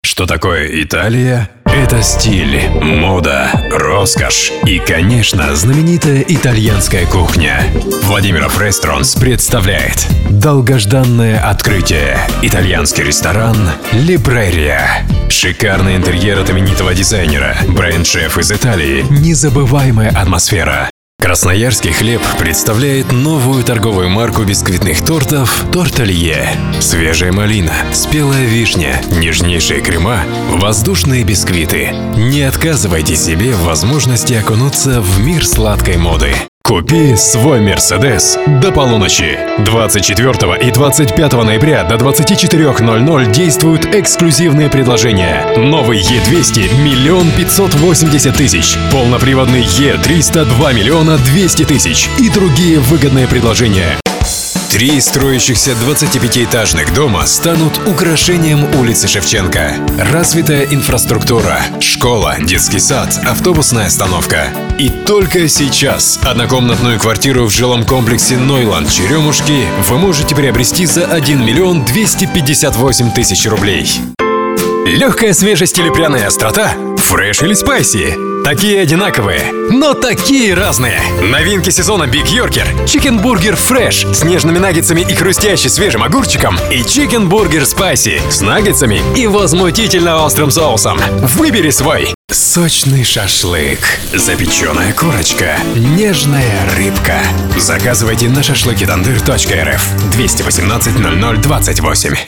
Russian Male Voice Over Artist
Male
Authoritative, Bright, Character, Confident, Cool, Corporate, Deep, Engaging, Friendly, Natural, Sarcastic, Soft, Warm, Versatile
High-quality professional equipment and a sound booth are used.
You get a clean and ready-to-use voice-over. 100% Russian - No accent.
Microphone: Rode K2, Sennheiser MKH 416